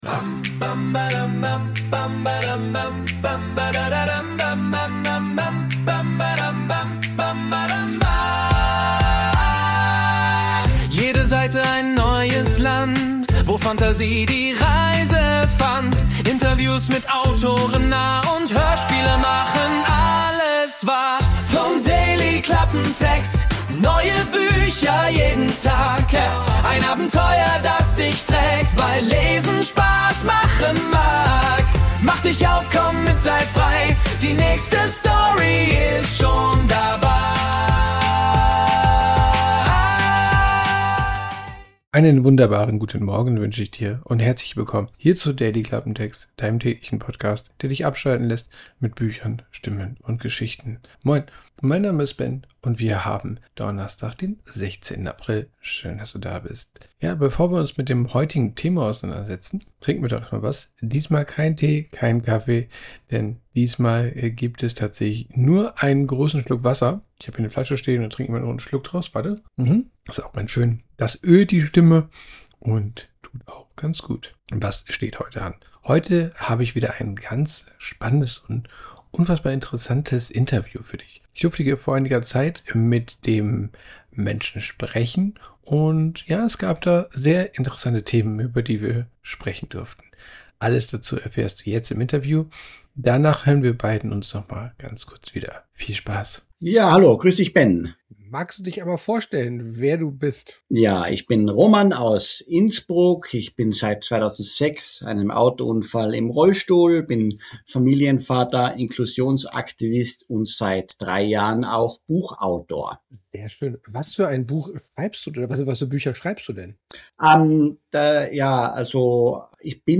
Interview ~ Dailyklappentext Podcast